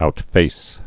(out-fās)